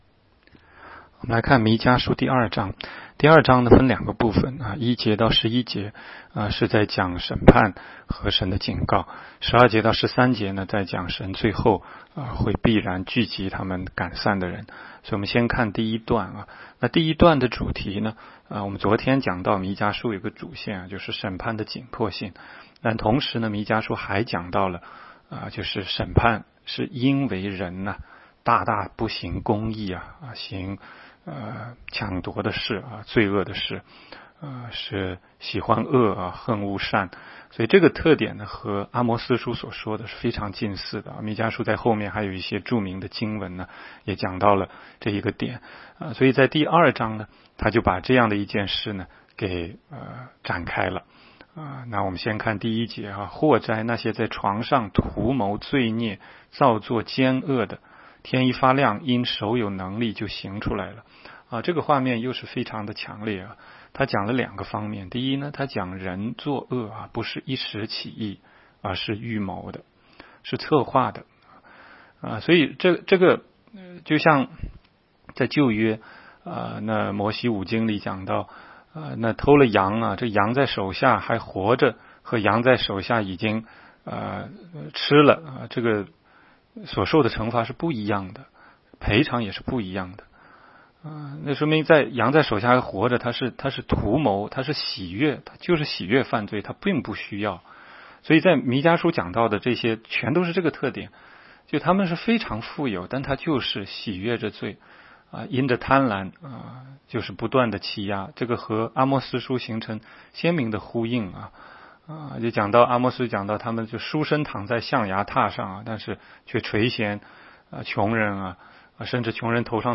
16街讲道录音 - 每日读经 -《弥迦书》2章